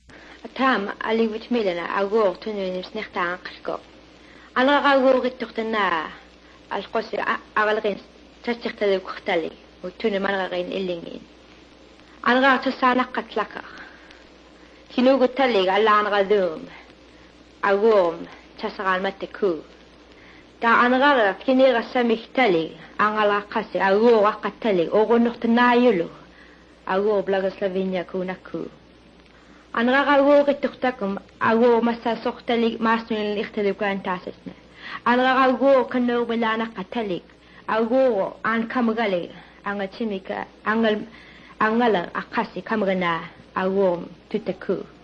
Parts of the recording reminded me of Greek and Nahuatl, but the overall sound is very unique, with lots of those uvular fricatives.
This particular variety of Aleut is spoken on the Pribilof Islands.